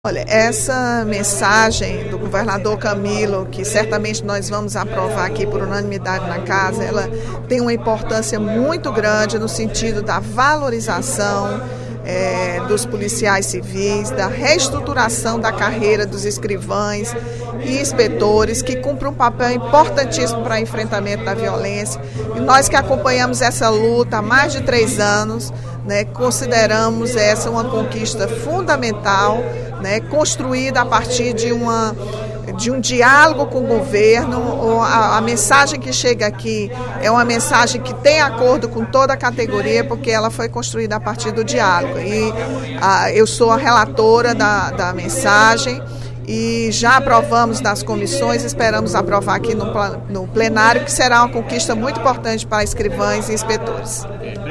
A deputada Rachel Marques (PT) fez pronunciamento nesta quinta-feira (10/03), durante primeiro expediente da sessão plenária, para destacar o projeto de lei 20/16, oriundo da mensagem nº 7.964, do Poder Executivo, aprovado na manhã de hoje nas comissões técnicas, que trata sobre a reformulação das carreiras de escrivão e de inspetor da Polícia Civil. A parlamentar defendeu a aprovação do projeto, que, segundo ela, beneficiará cerca de 2.700 servidores públicos.